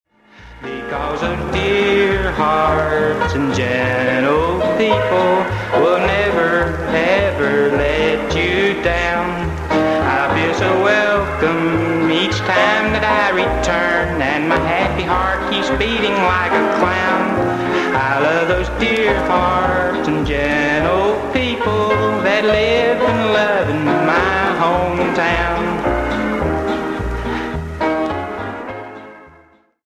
recording sessions